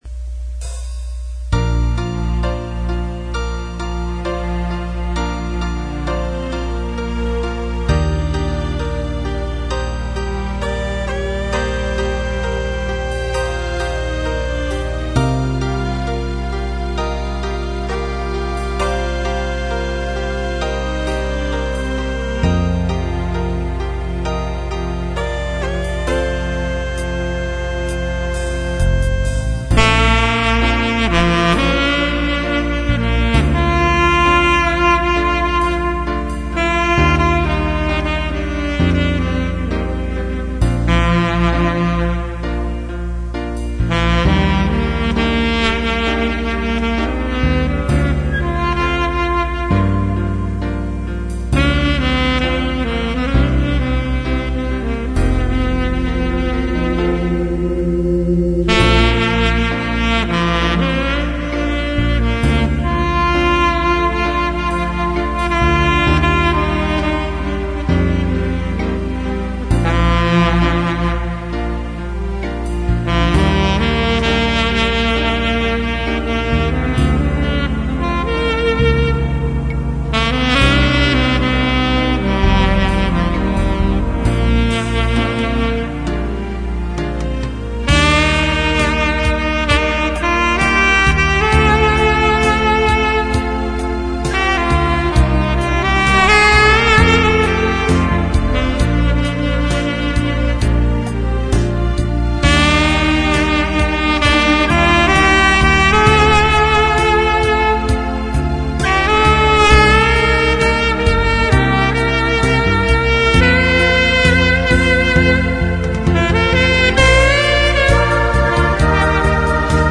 앨토
색소폰 음악정원